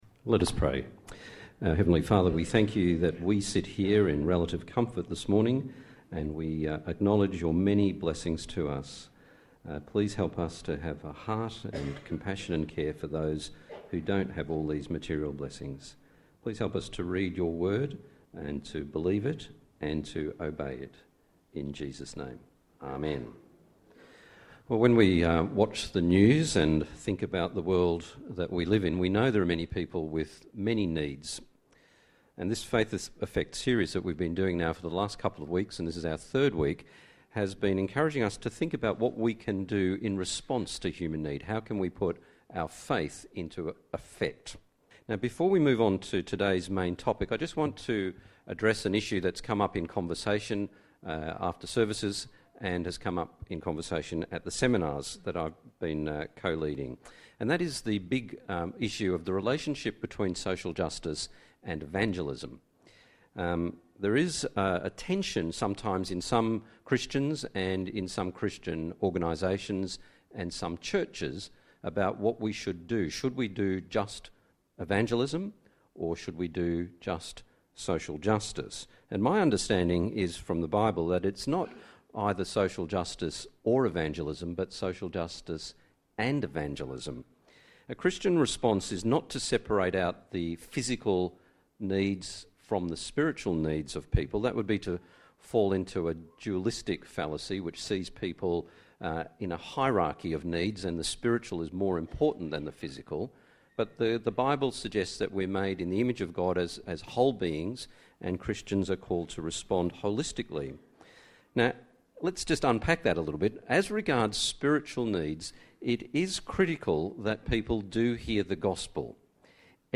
Download Download Bible Passage Leviticus 19:9-10, Proverbs 31:8, 1 John 3:16-18 Today is the third of four Sundays looking at global poverty and social justice issues.